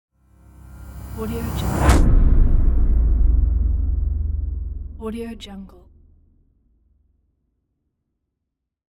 دانلود افکت صوتی اعلام یک عنوان